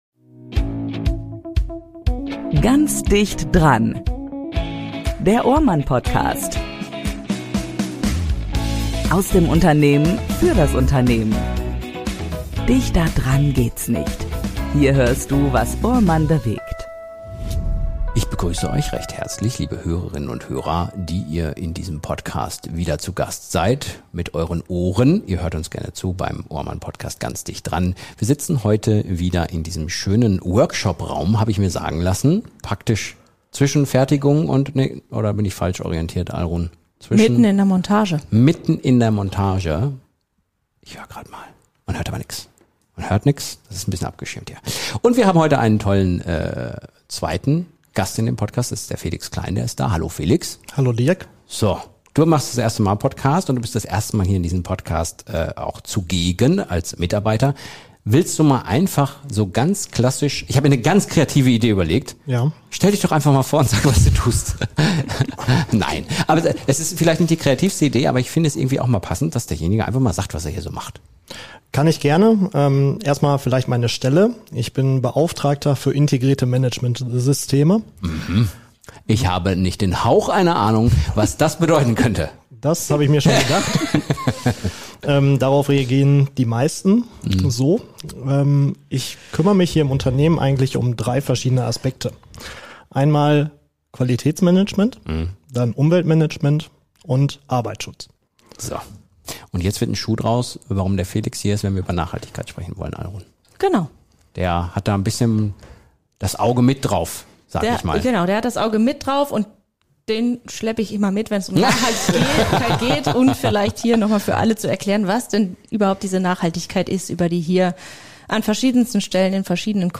Gemeinsam sprechen die drei über das Thema Nachhaltigkeit – was es für ihr Unternehmen bedeutet, warum es weit mehr umfasst als nur ökologische Aspekte und wie sich soziale, ökonomische und ökologische Dimensionen miteinander verbinden.
Ein Gespräch über Haltung, Prozesse und die langen Wege, die echte Nachhaltigkeit in Unternehmen erfordert.